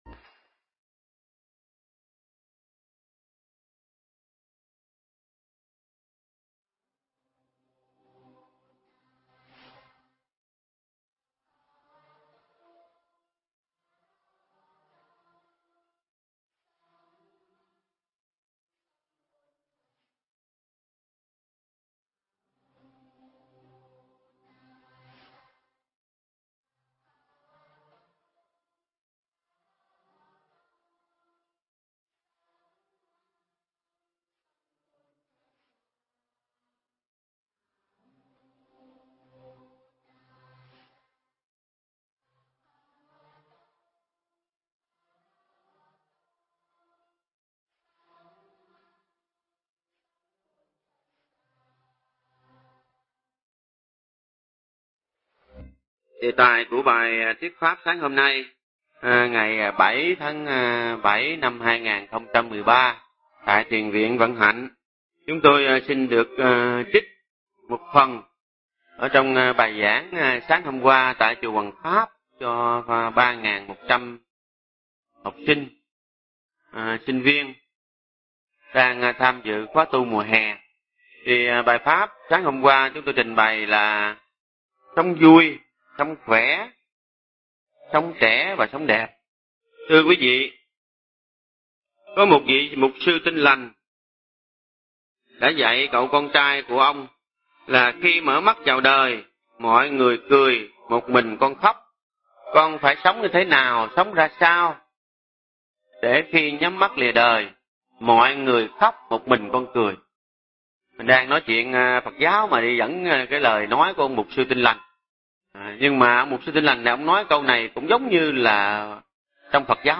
Nghe Mp3 thuyết pháp Sống Đẹp
Mp3 pháp thoại Sống Đẹp